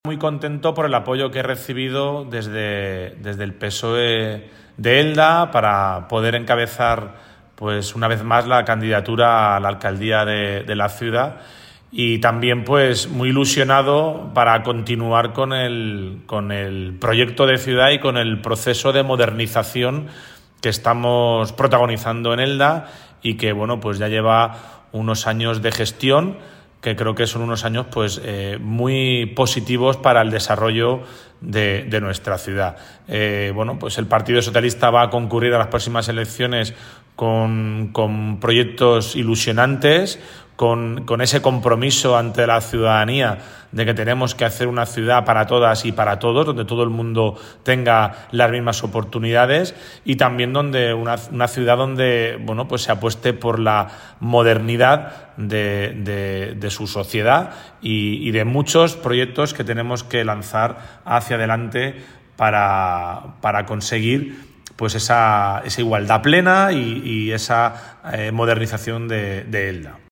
Declaraciones de Rubén Alfaro Alcalde de Elda